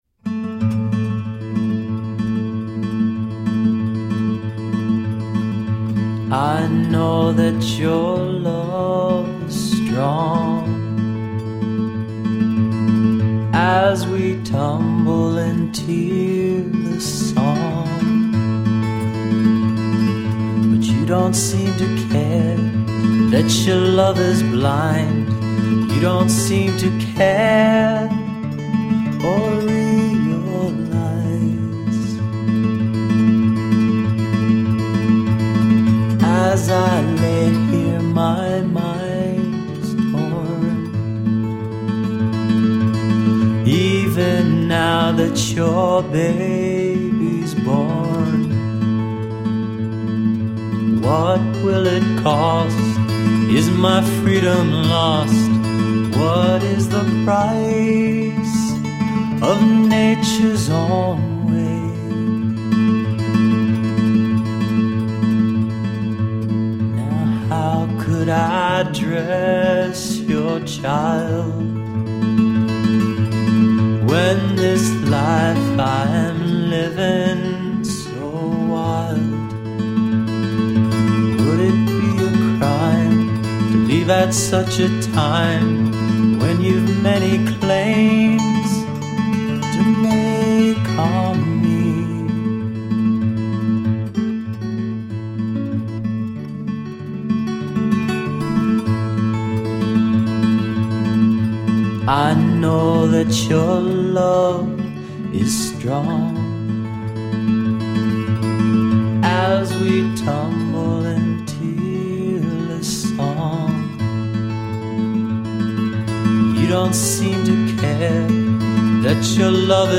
An introspective mix of acoustic guitar based songs.